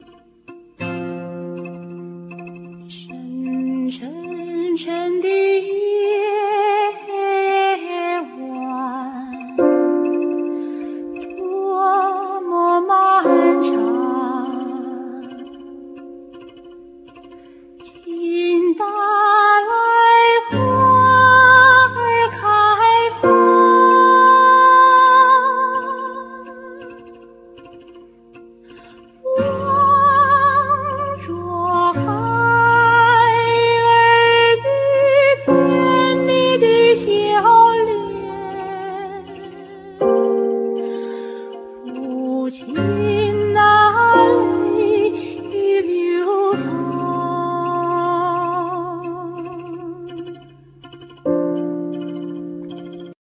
Voclas
Violin,Bariton violin,Viola
Guiatr,Mandlin,Cello,Percussions,Zither,Kobala,Vocals
Piano,Cembalo,Guitar
Percussion,Drums